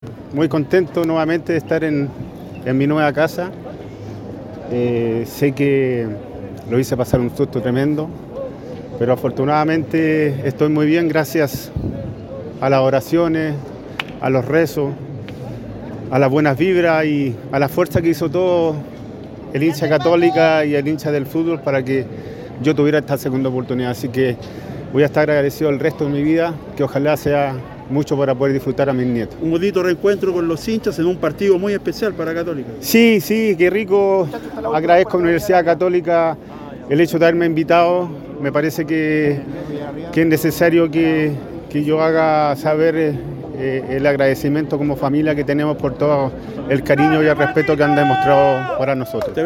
Patricio Toledo en diálogo con ADN Deportes